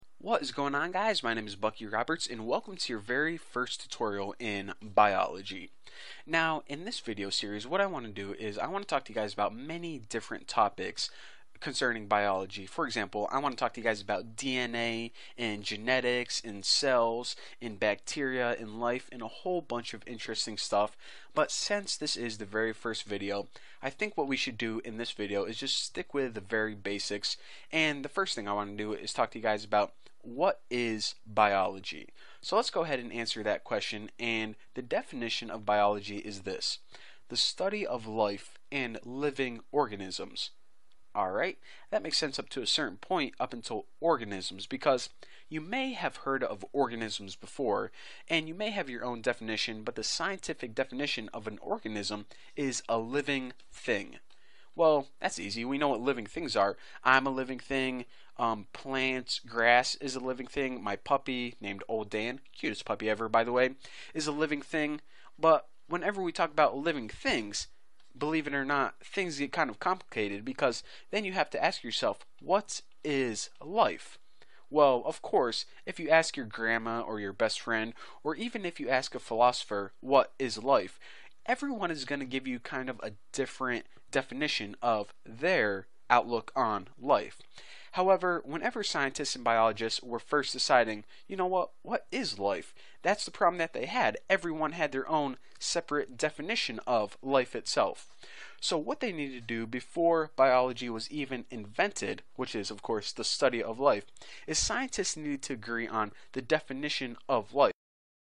Sample: You will hear an Interview/Lecture.